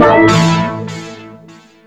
NFF-cool-success.wav